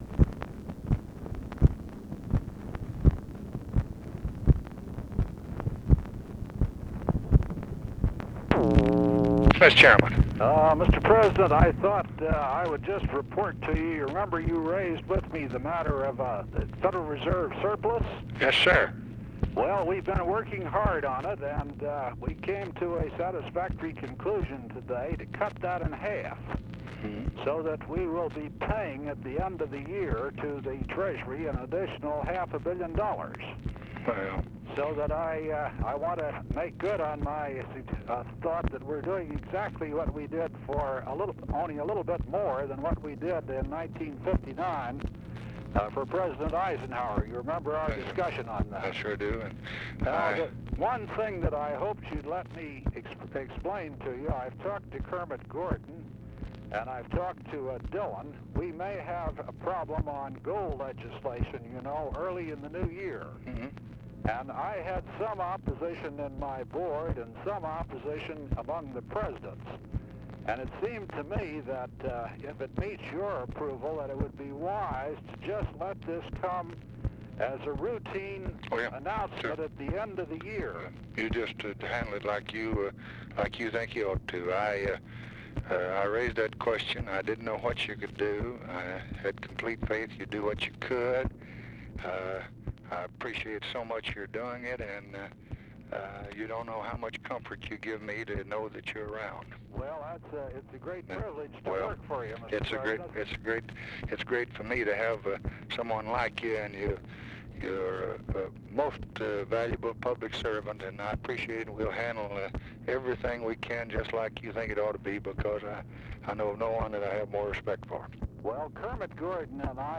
Conversation with WILLIAM MCC. MARTIN, December 16, 1964
Secret White House Tapes | Lyndon B. Johnson Presidency Conversation with WILLIAM MCC.